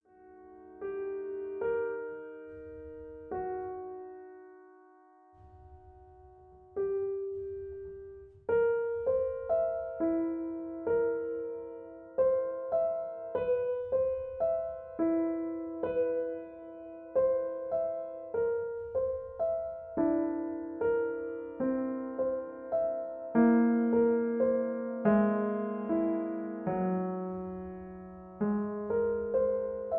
MP3 piano accompaniment
in A (original key)